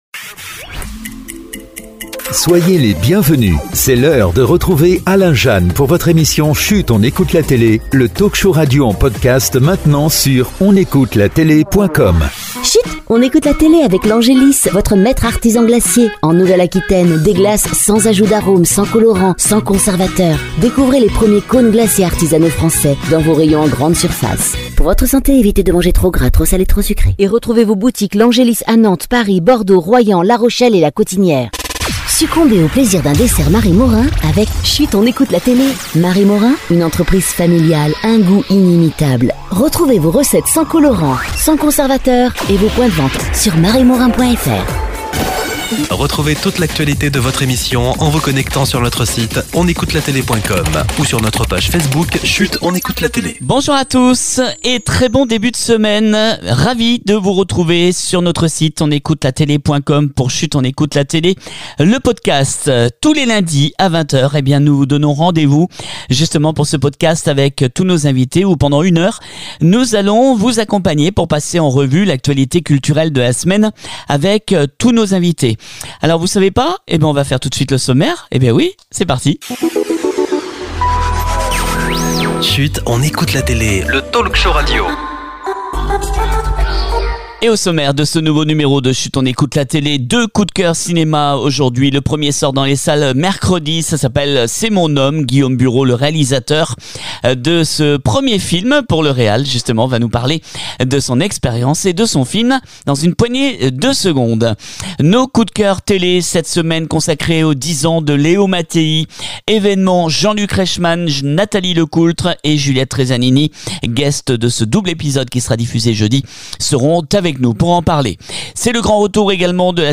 On se retrouve pour une émission 100% inédite ce lundi 3 Avril avec 2 coups de coeur cinéma et 2 coups de coeur télé